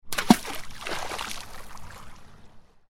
Small-water-splash-sound-effect.mp3